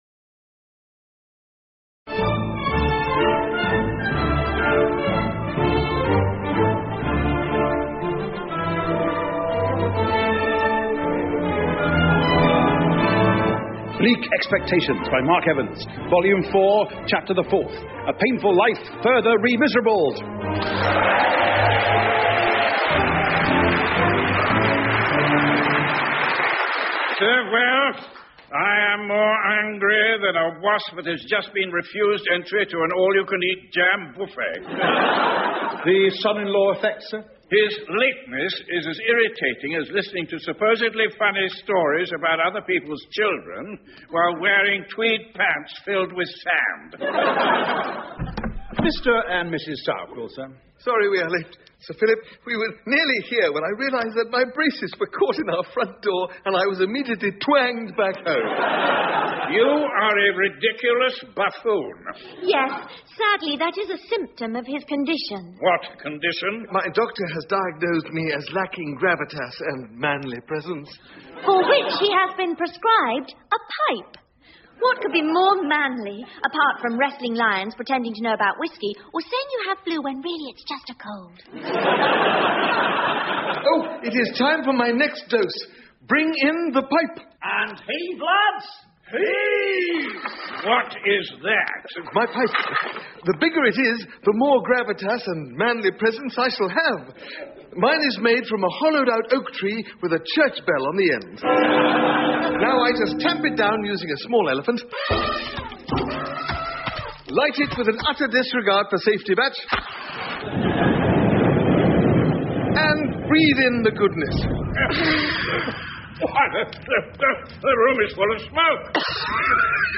英文广播剧在线听 Bleak Expectations 106 听力文件下载—在线英语听力室